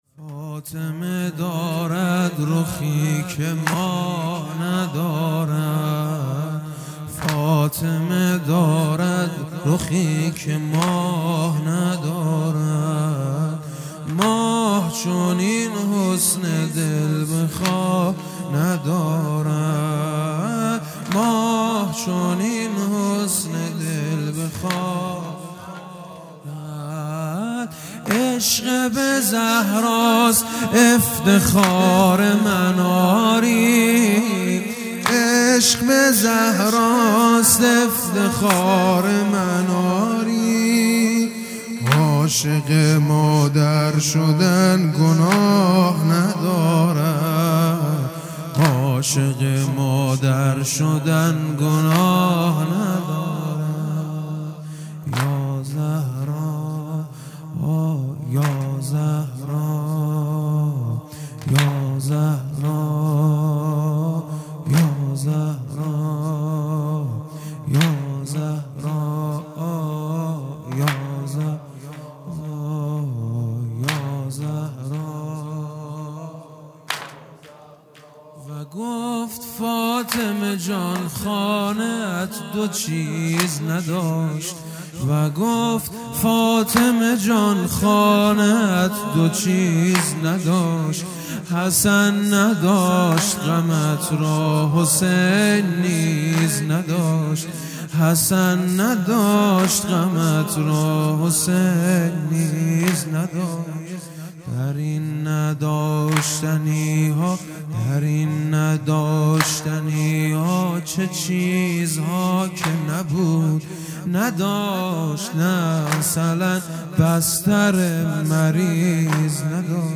جلسه هفتگی ۴ دی ۹۷